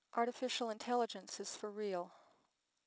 Samples for 2 male and 2 females chosen for their many high-frequency sounds:
sx126_1: Female (DR1/FELC0)
All files use MFCC-based BWE. The "dynamic" version are based on I=128, J=4, Nmin=500, l=3, and Tau=4 (40ms), i.e., narrowband representation has dim 40 (frames at time -120, -80, -40, and 0 ms)